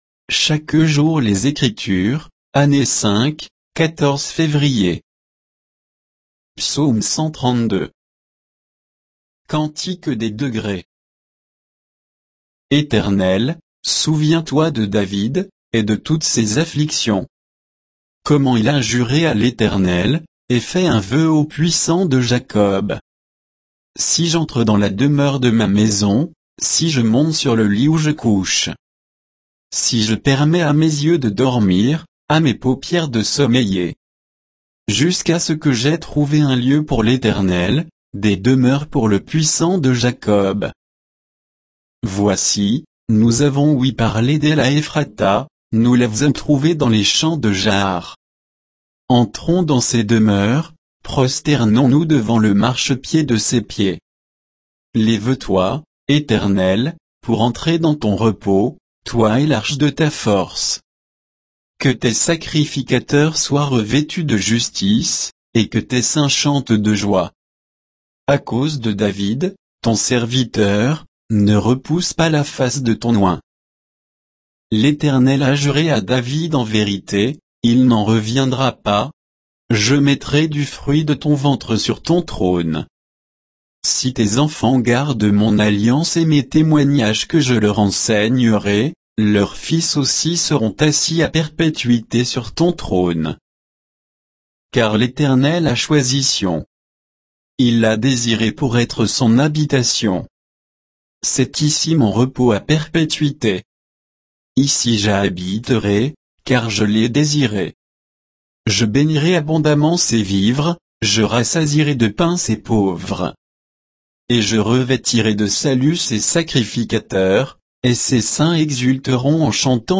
Méditation quoditienne de Chaque jour les Écritures sur Psaume 132